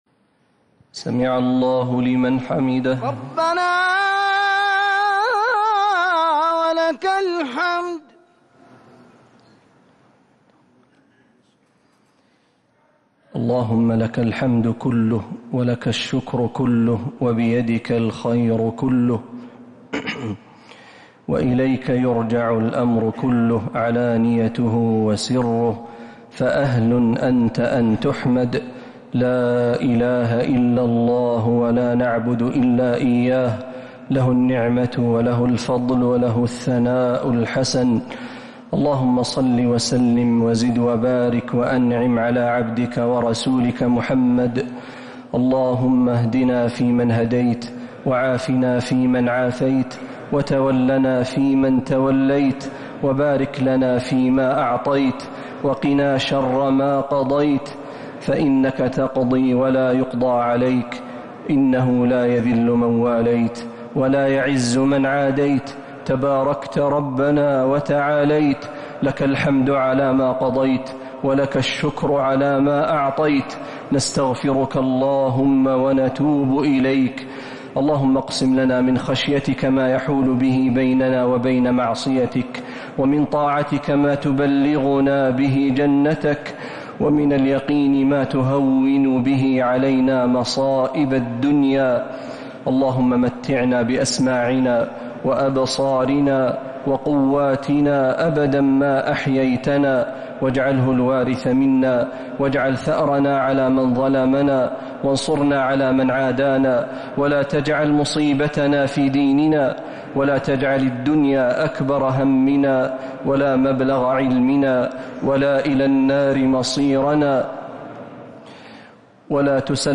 دعاء القنوت ليلة 1 رمضان 1447هـ | Dua 1st night Ramadan 1447H > تراويح الحرم النبوي عام 1447 🕌 > التراويح - تلاوات الحرمين